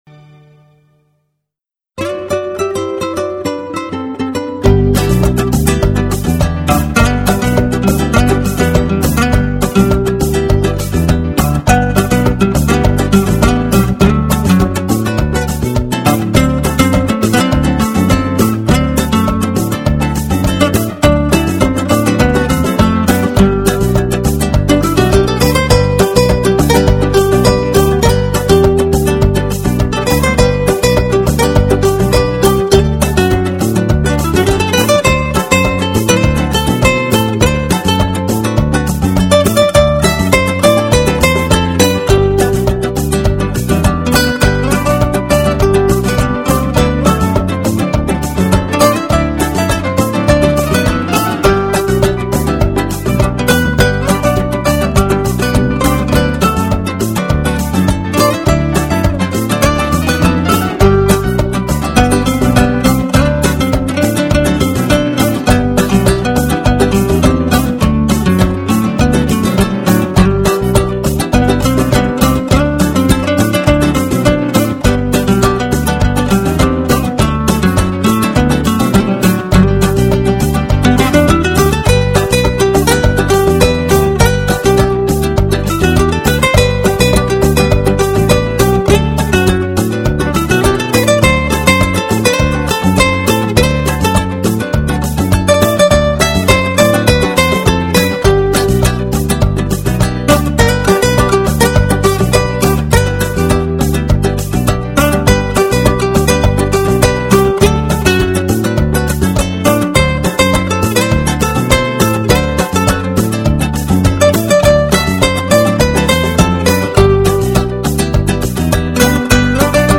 0207-吉他名曲玛丽亚之歌.mp3